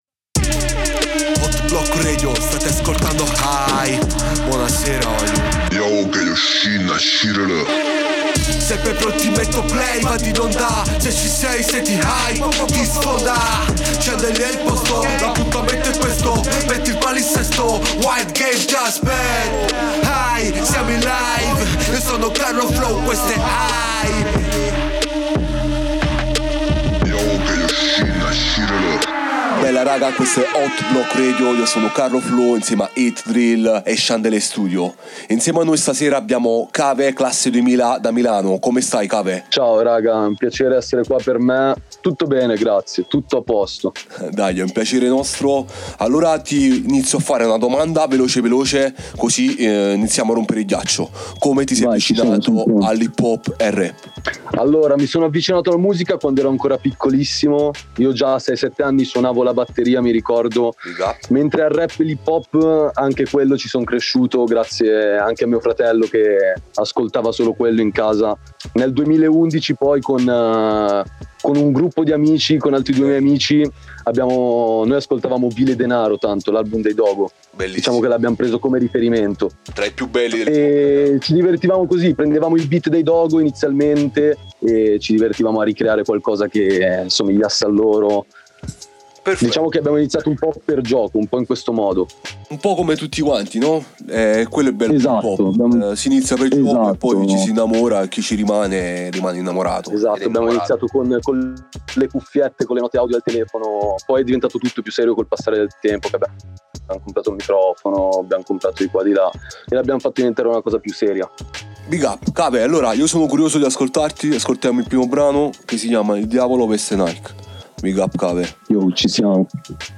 Una chiacchierata bella intensa